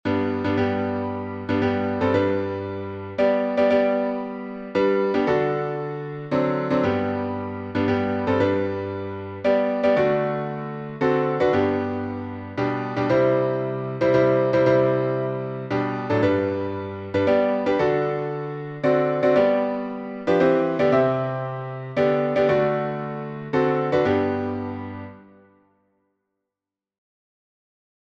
Jesus Saves — alternative rhythm — JESUS SAVES.
Jesus_Saves_altRhythm.mp3